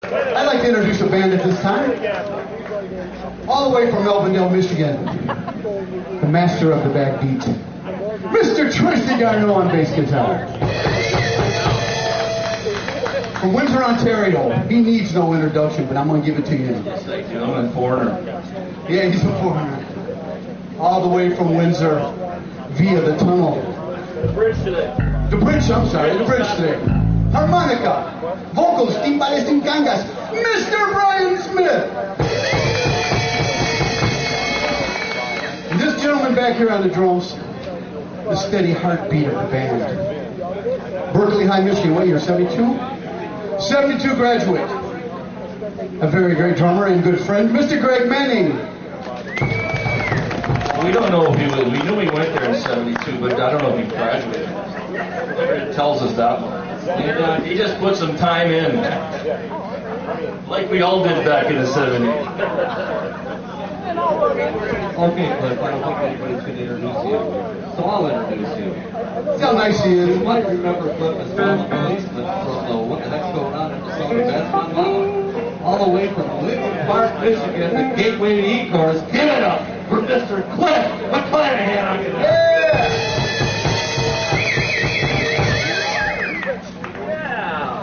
A camcorder was turned on and basically just left on a picnic table, somewhat away from the stage, with people walking in front of the camera much of the time (to/from the food tent, especially at first...), with everyone chattering away, all as one might expect, but obviously not condusive to ensuring high quality audio/video recordings...as no website presentation was contemplated at the time.